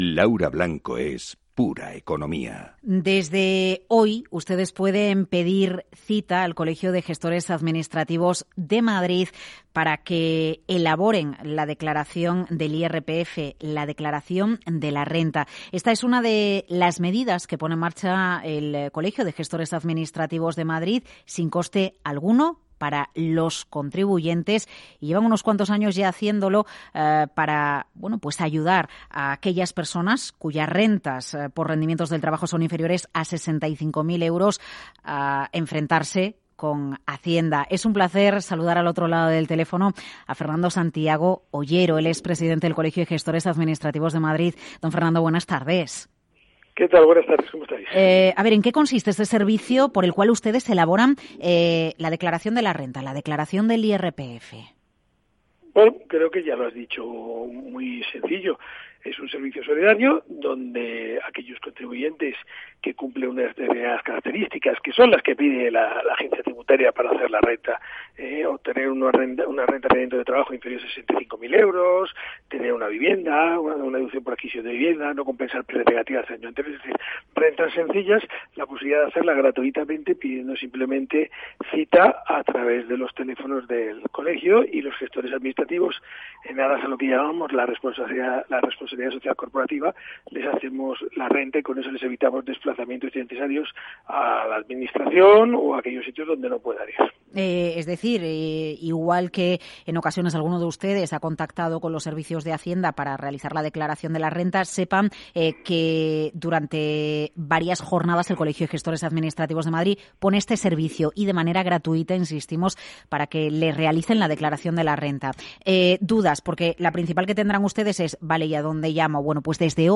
Entrevista en Capital Radio
entrevista_capitalradio.mp3